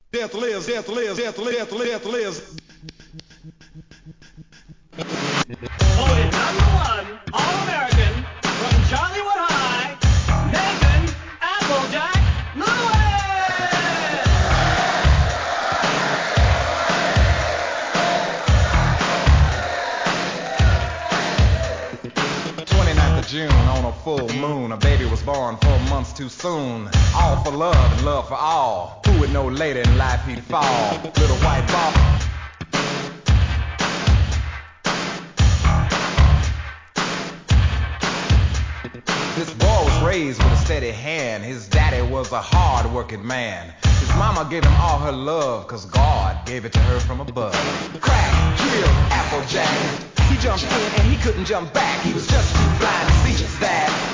HIP HOP/R&B
OLD SCHOOL HIP HOP!!